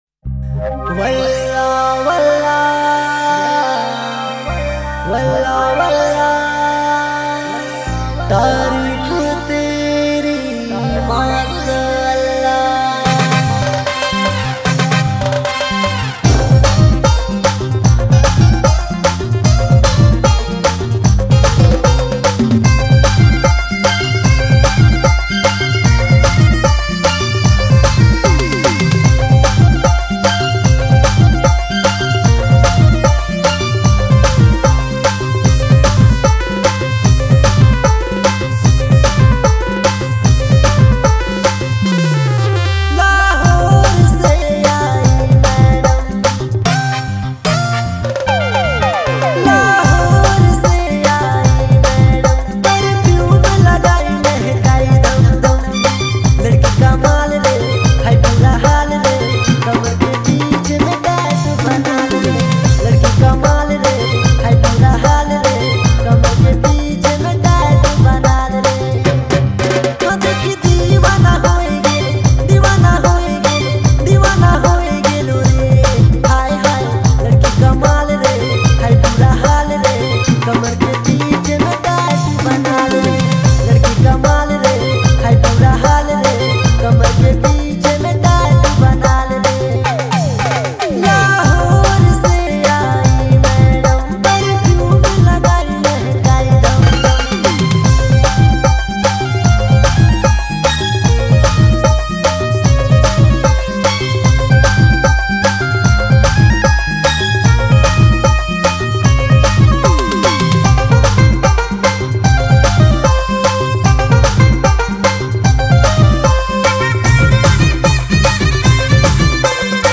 Dj Remixer
New Latest Nagpuri Song